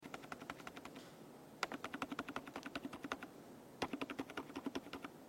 The sound profile largely depends on the switch type, here’s a comparison of typing sounds from different Akko switches, tap the product images below to hear how each switch sounds in action:
Akko Fairy Switch (Silent Linear)
How-Loud-Are-Mechanical-Keyboards-Akko-Fairy-Switc.mp3